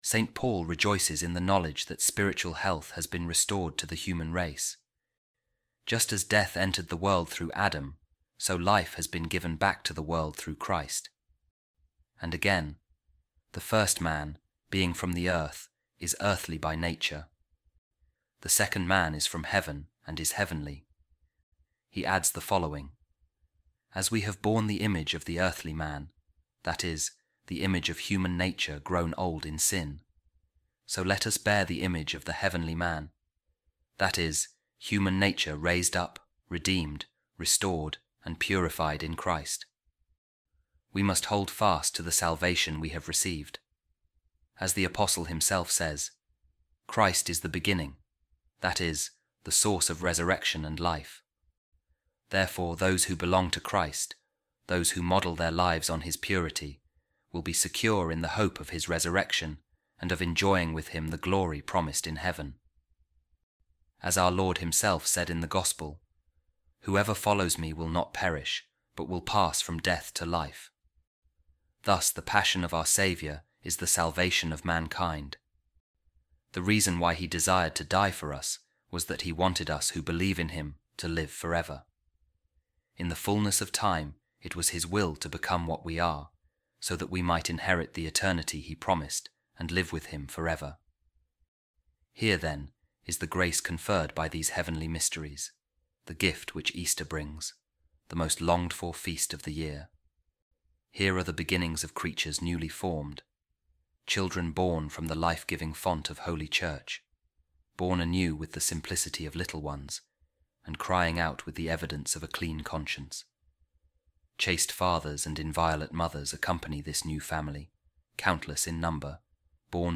Office Of Readings | Easter Wednesday | A Reading From A Paschal Homily Of An Ancient Author